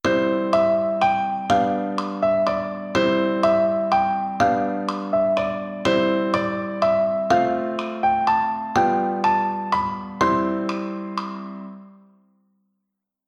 Nun gleich noch einmal mit Metronom:
KlavierkursNr051EhNochDerLenzBeginntMM.mp3